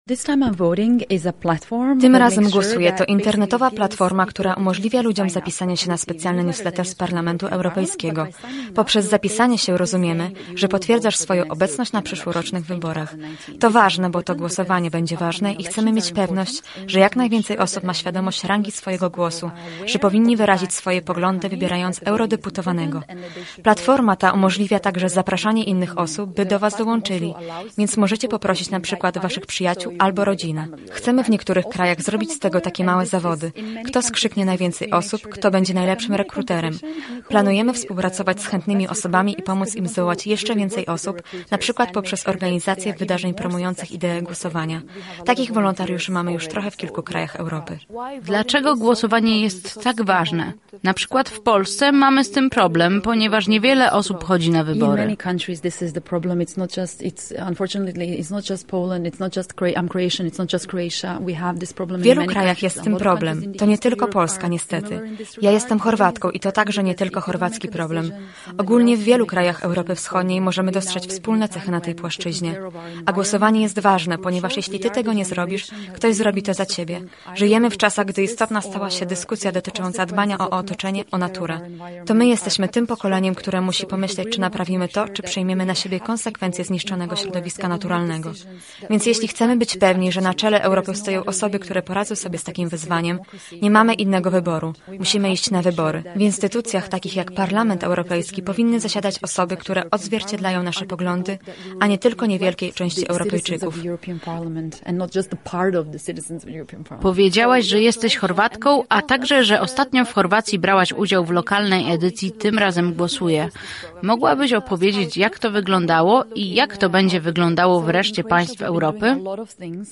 głosuję-z-tłumaczeniem.mp3